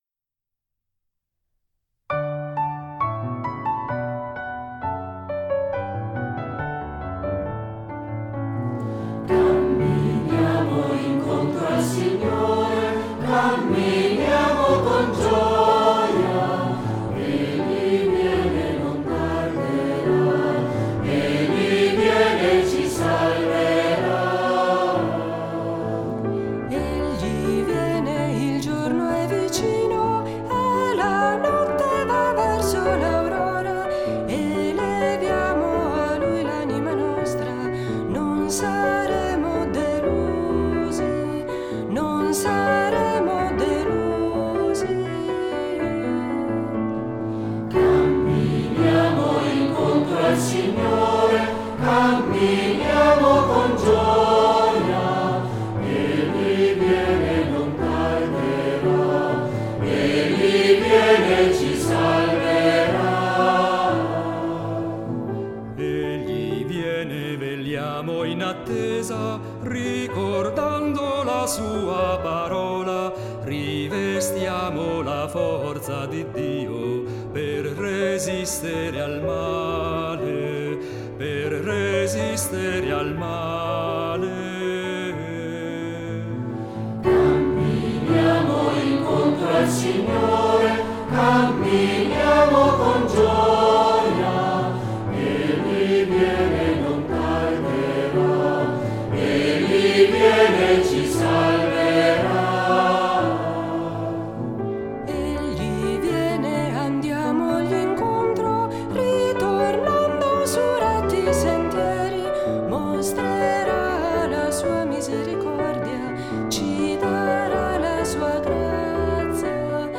La terzina è una figura ritmica che da’ movimento al canto, gli dona un carattere non scontato e concede (se eseguita bene!) al coro e all’assemblea di lasciare andare il cuore e il corpo (è infatti adatto per le processioni d’ingresso, di offertorio e di Comunione) nell’esperienza globale del rito.